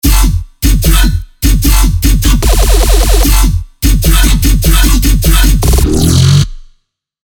精心打造了这款包含 200 个震撼舞池的低音单音和低音循环的杰作。
期待这些超强 dubstep 低音，让你的 drop 更具冲击力！
所有采样均已标注调性，BPM 为 150。